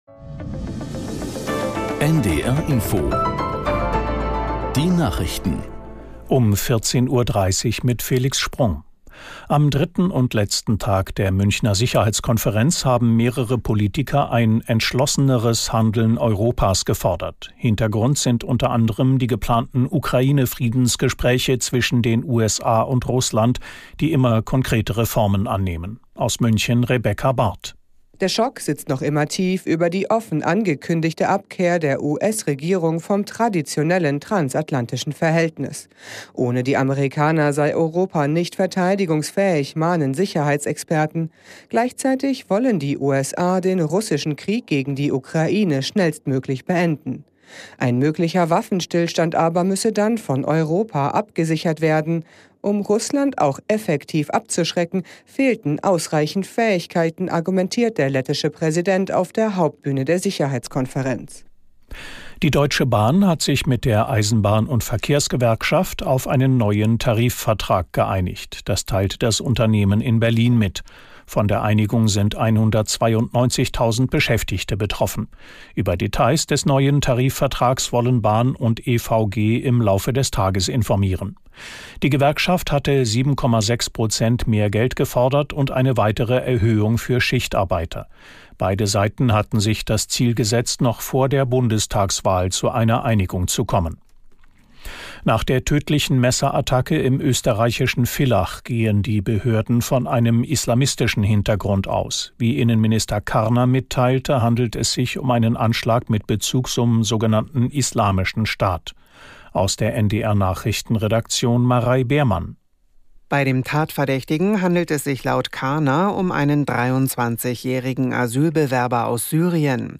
Nachrichten - 16.02.2025